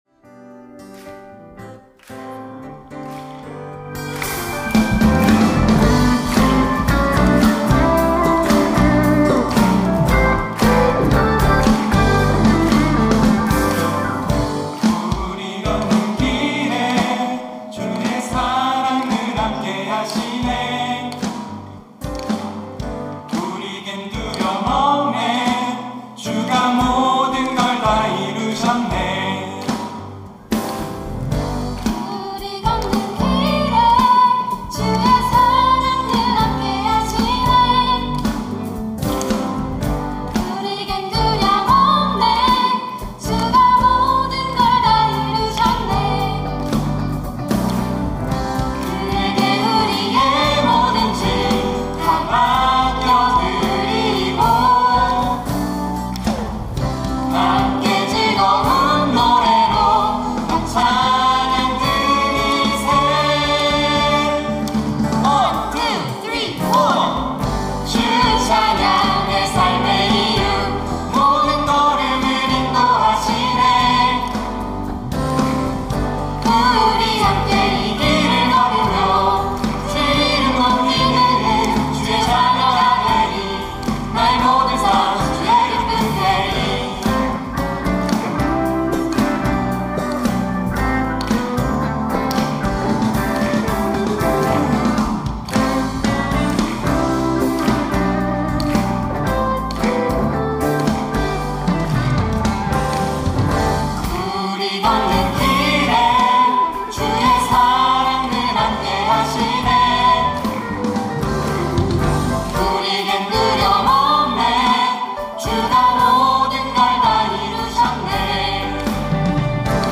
특송과 특주 - 주 찬양 내 삶의 이유